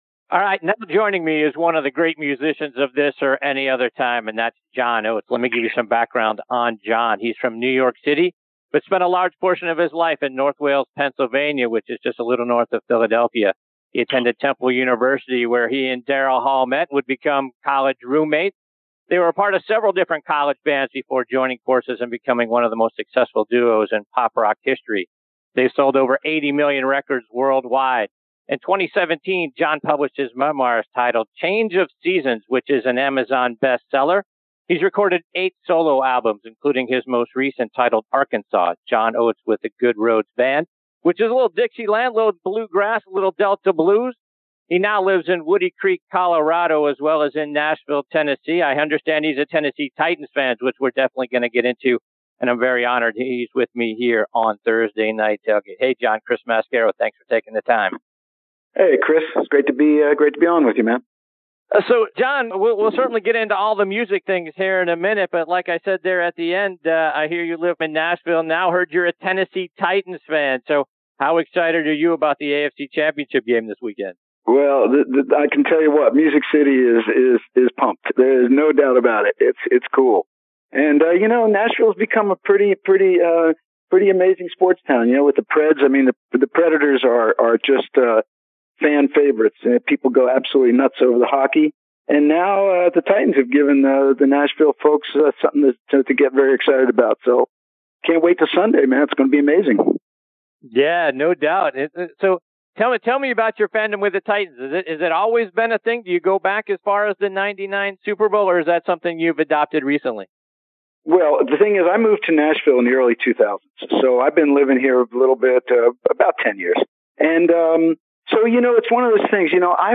John Oates joins us on this segment of Thursday Night Tailgate. John talks about living in Nashville and his excitement over the Tennessee Titans season.